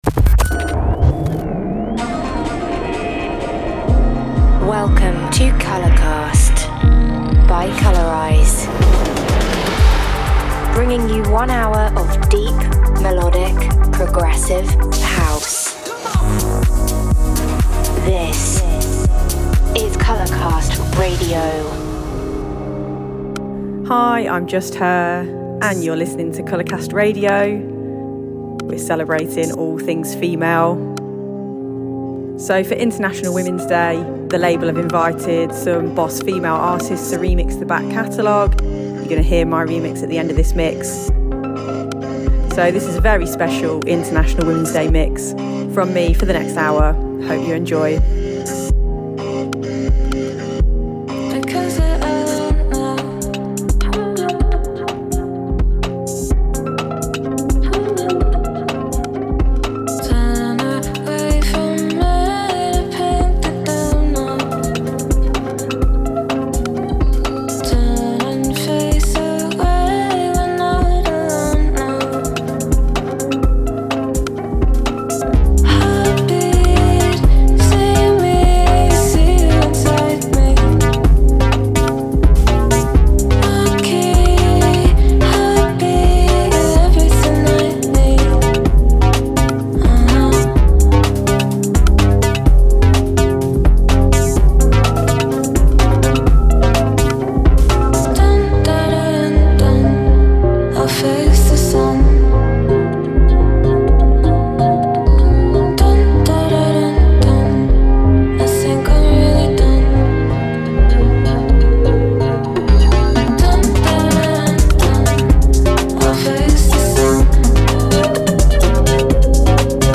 the finest music from female producers only!